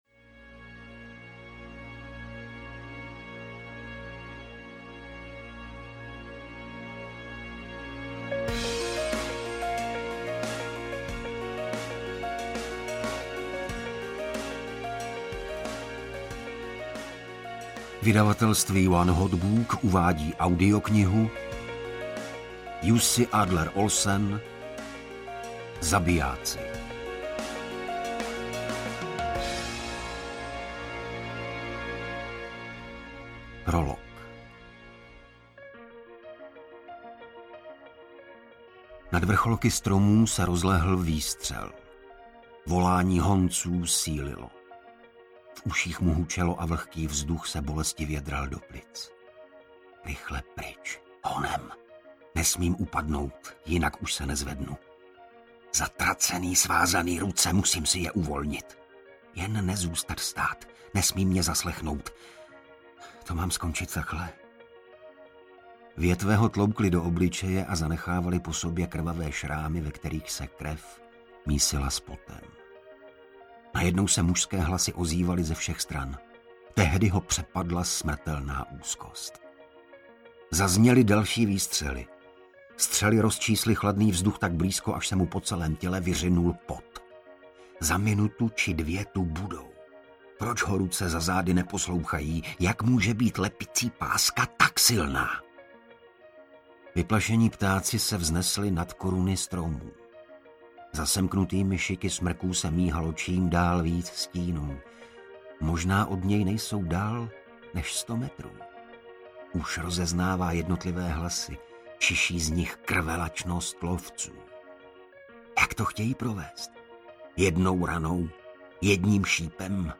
Zabijáci audiokniha
Ukázka z knihy
• InterpretIgor Bareš